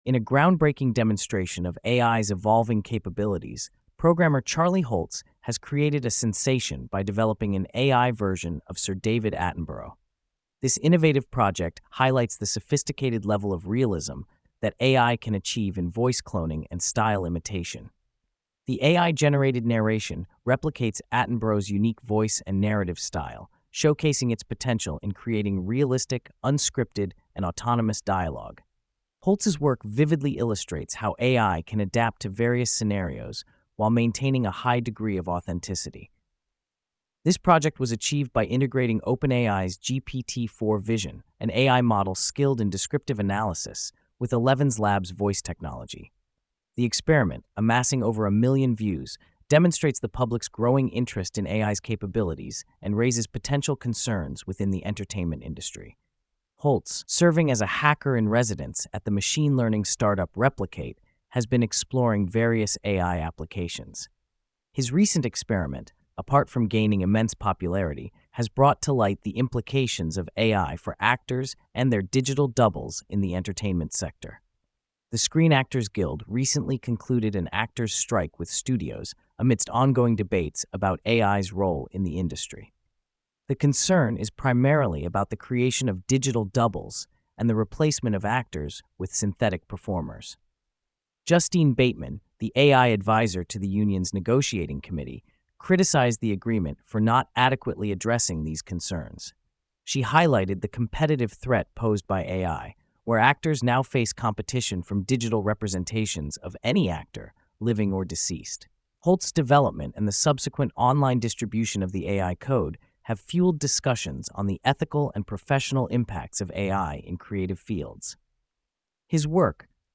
AI Impersonates David Attenborough's Voice Perfectly - Social Media in Frenzy!
The AI-generated narration replicates Attenborough's unique voice and narrative style, showcasing its potential in creating realistic, unscripted, and autonomous dialogue.